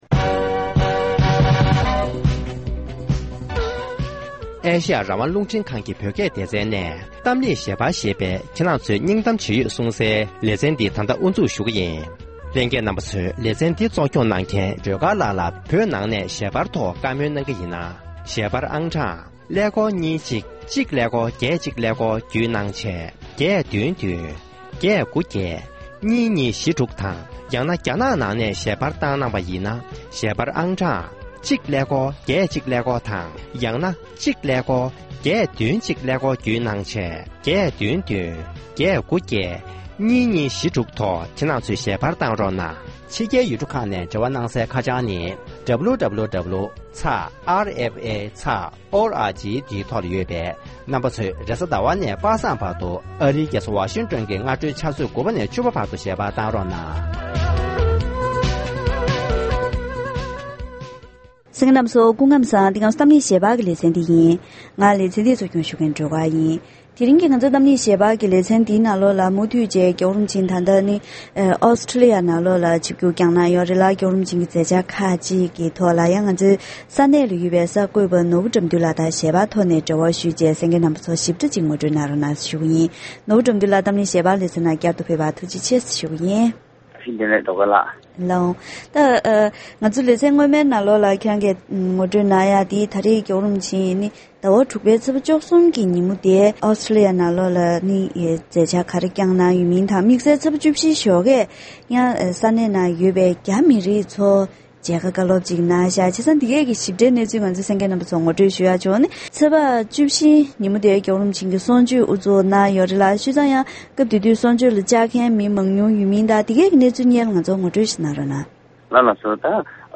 སིཌ་ནིར་མཇལ་ཁ་བཀའ་སློབ།
༸གོང་ས་མཆོག་ནས་སིཌ་ནི་རུ་བོད་མི་དང་སོག་པོ་འབྲུག་པ་བཅས་ལ་མཇལ་ཁ་བཀའ་སློབ་སྩལ་བ།